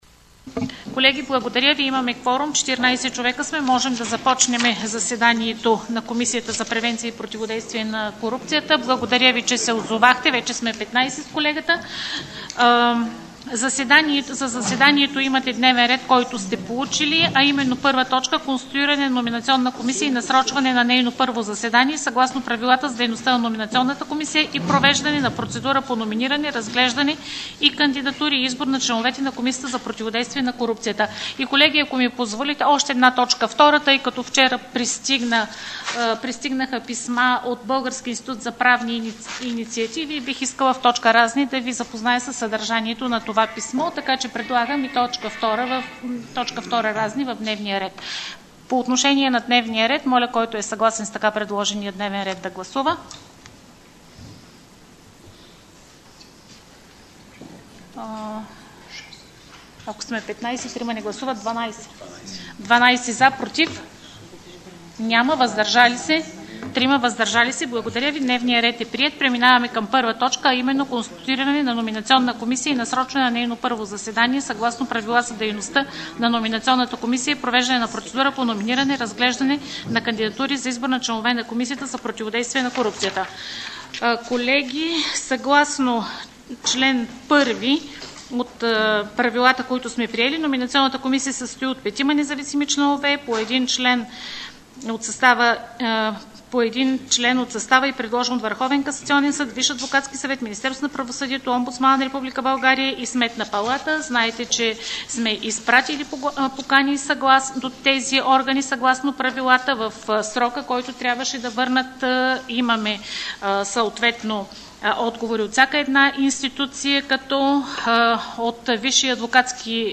ZASEDANIE_KOMISIA_ZA_PROTIVODEISTVIE_S_KORUPCIATA_14.50H_31.07.25.mp3